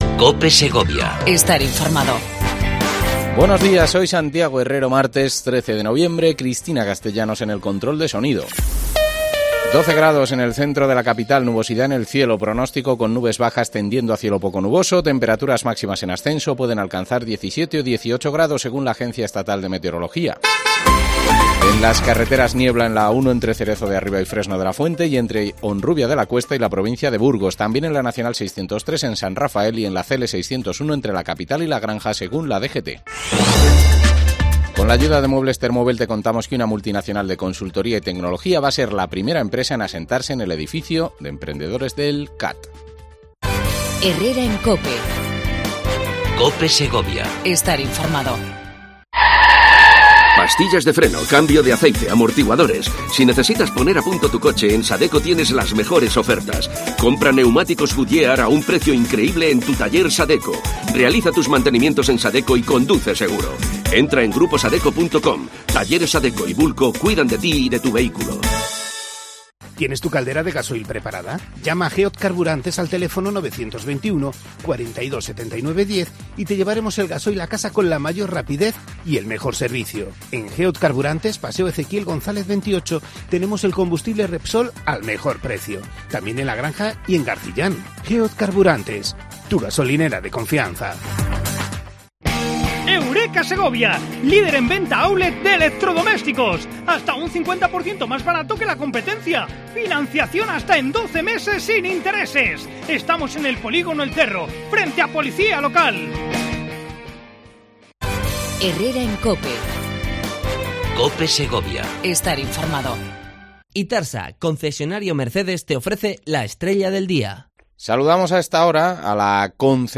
AUDIO: Entrevista a Paloma Maroto, Concejala de Obras, Servicios e Infraestructuras del Ayuntamiento de la capital segoviana